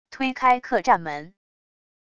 推开客栈门wav下载